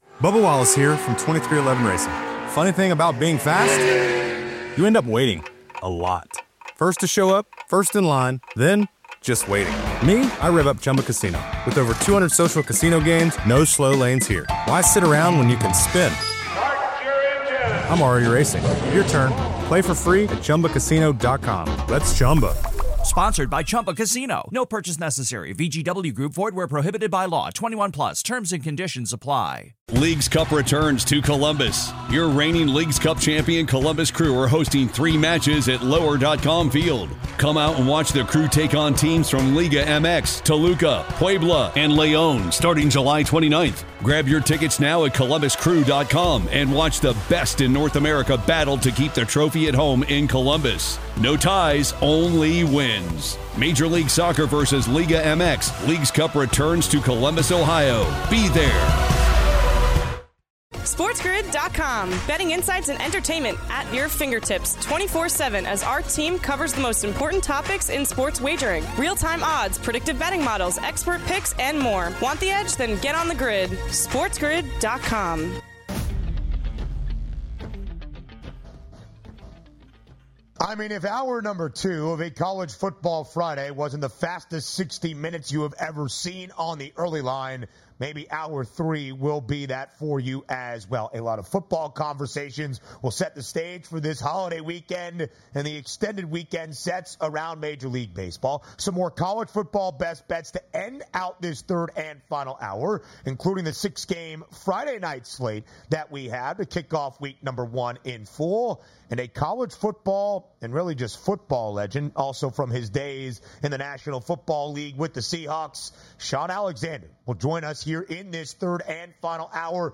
The guys are also joined by special guest Shaun Alexander to talk his freshman award and his hopes for Alabama this season.